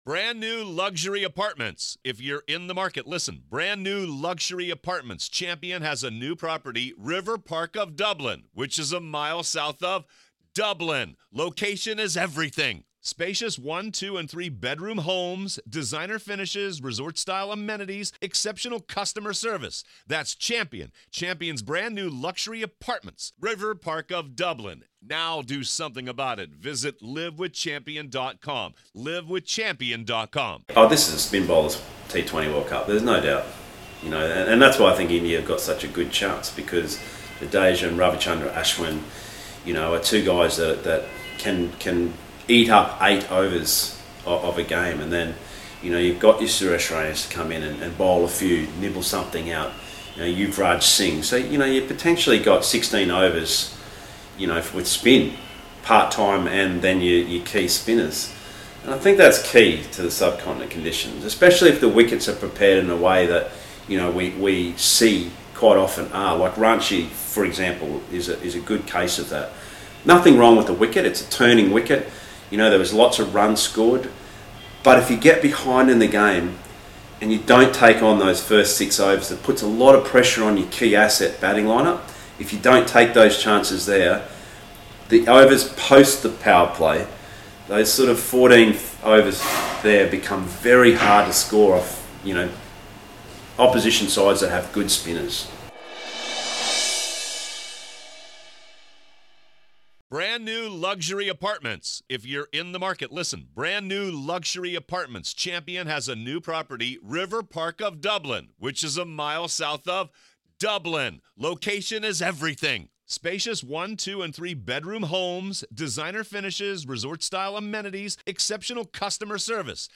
The T20 World Cup is likely to be dominated by spin. Hear Matthew Hayden talk about how much of a role spin will play.